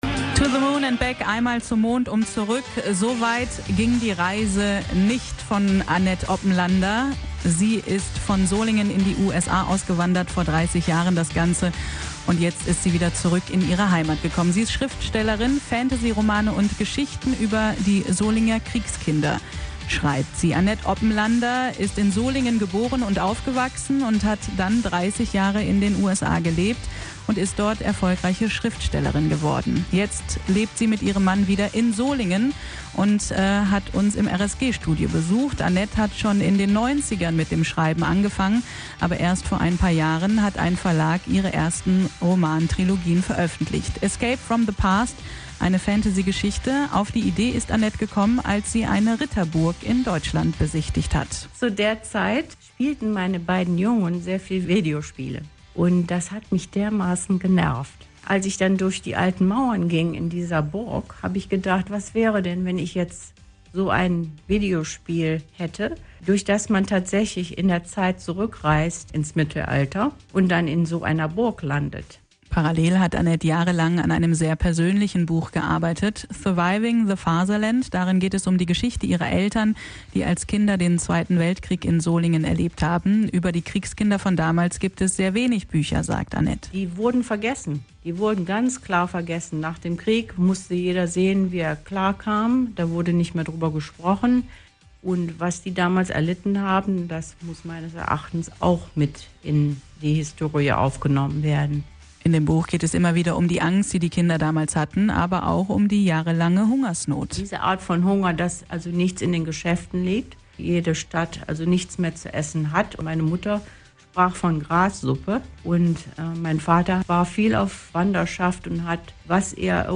Radio RSG Solingen Interview